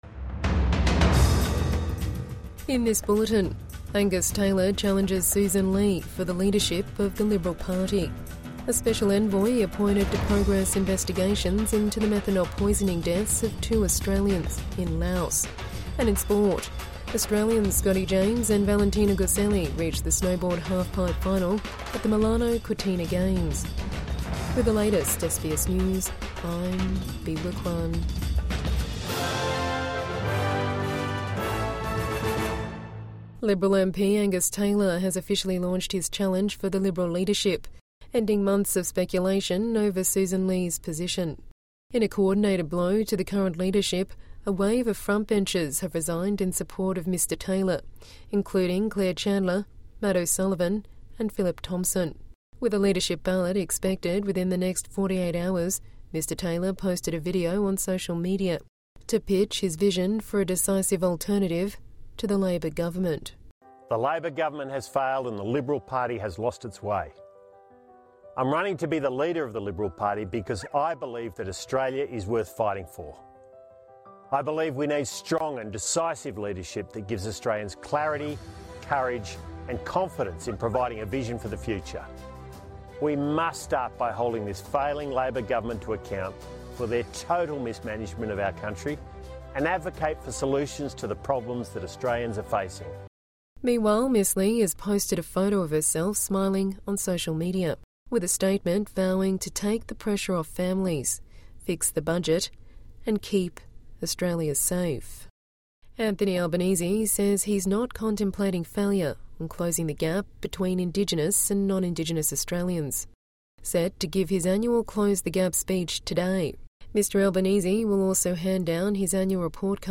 Angus Taylor challenges Sussan Ley for leadership of Liberal Party | Midday News Bulletin 12 February 2026